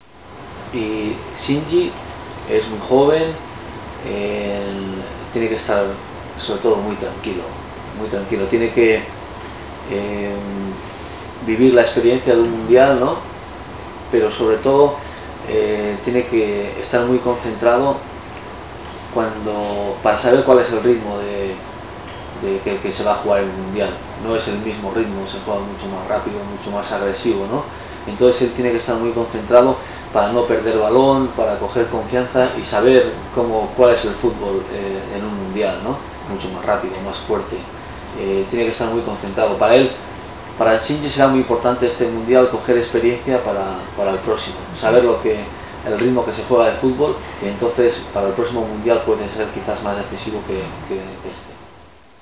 ワールドカップ出場経験者であるベギリスタイン選手にワールドカップについて、そして浦和レッズから代表として出場する３人の選手についてのコメントを語ってもらいました。